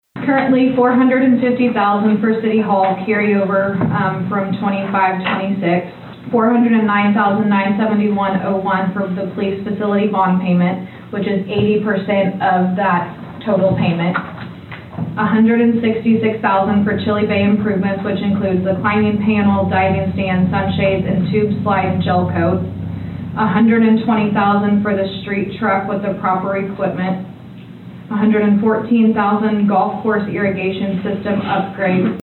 A public hearing for the City of Chillicothe’s proposed nearly $1.5 million in Capital Improvement Expenditures was held as part of the Chillicothe City Council meeting.
City Auditor Allison Jefferies listed the items that are over $100,000 each.